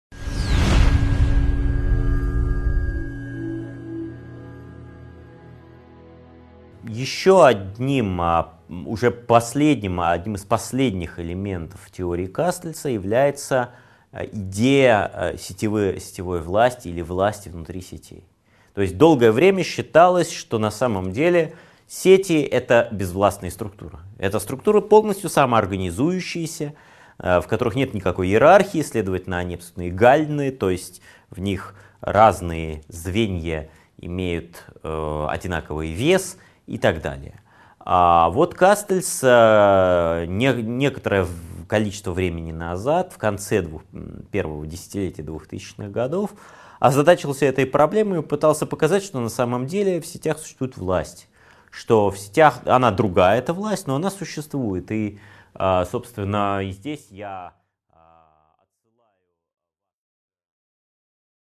Аудиокнига 8.7 Идеи медиадетерминизма и сетевого общества: Власть и сетевая структура (М. Кастельс) | Библиотека аудиокниг